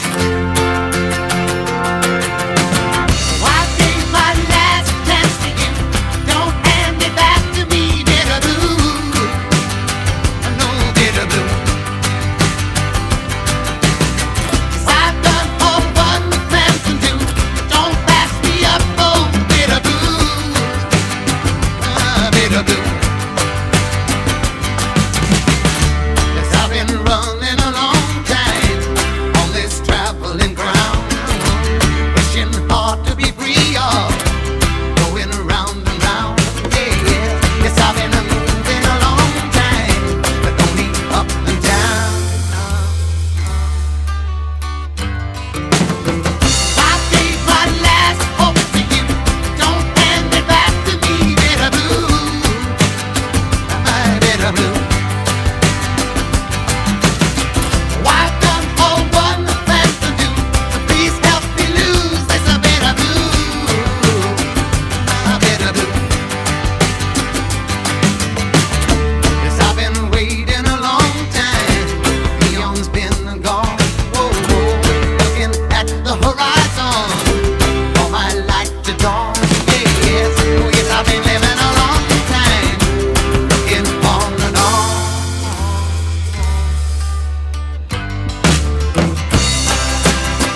ROCK / 70'S (UK)